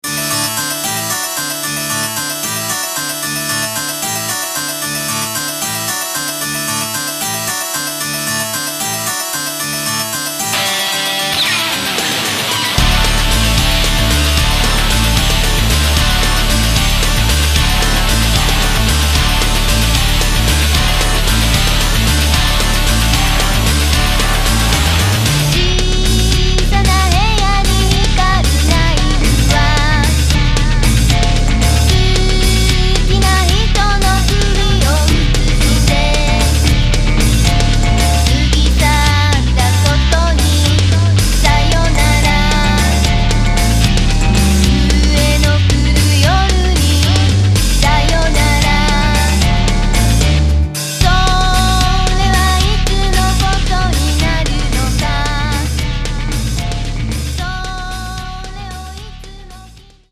Cyberpunk + Industrial + Goth + Deathrock